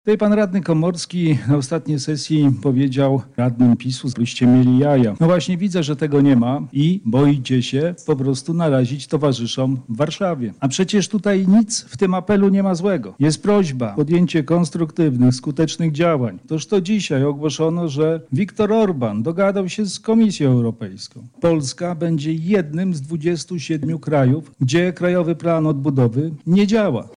Zakończyła się sesja Sejmiku Województwa Lubelskiego.
-mówił radny oraz przewodniczący Klubu Radnych PSL Sejmiku Województwa Lubelskiego, Marek Kos.